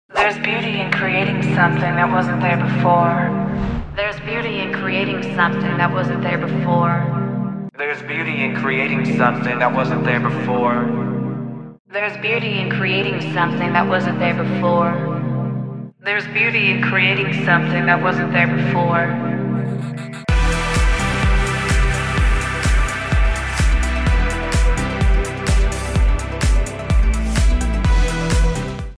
Now hear that same phrase, reborn in multiple voices using our AI vocal models.